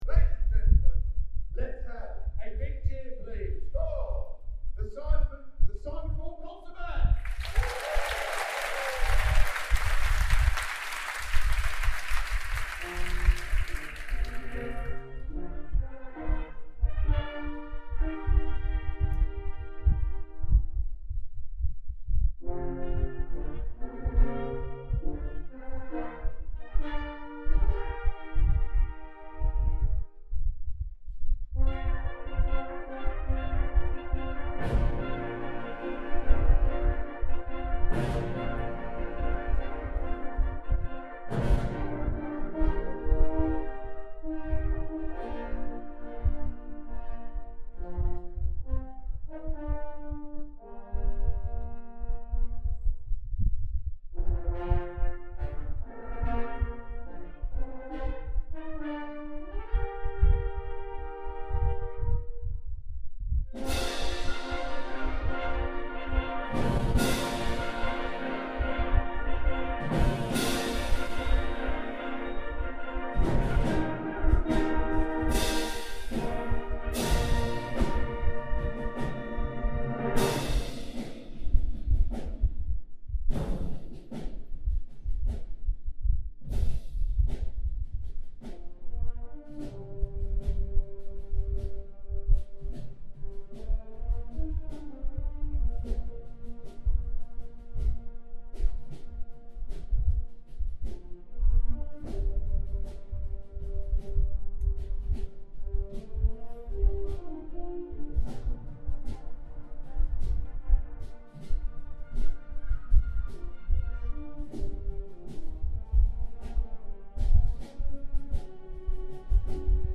Olympic Fanfare and Theme - Concert Band